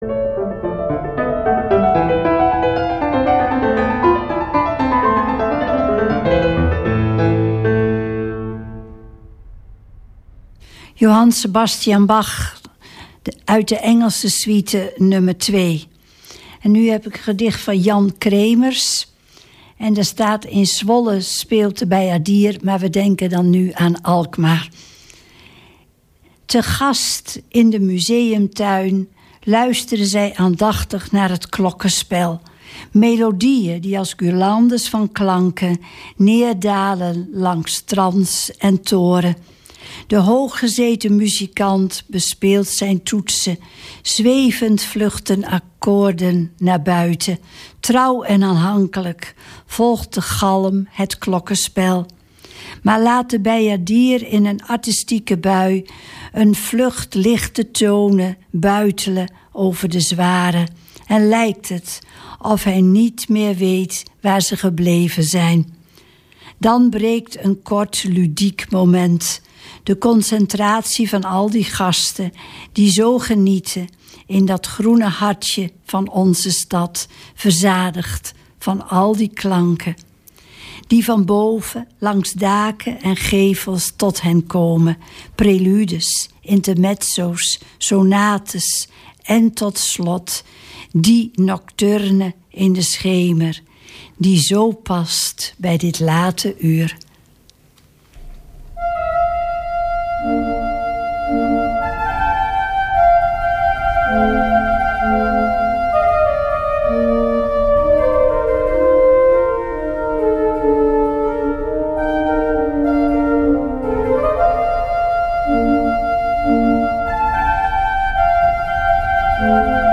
Iedere zondag te beluisteren van 19:00-21:00 uur. 19:00 – 20:00 uur: Klassieke muziek mix op zondag 20:00 – 21:00 uur: Jaargetijden, Klassieke muziek poëzie en verhalen.